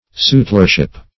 Sutlership \Sut"ler*ship\, n.
sutlership.mp3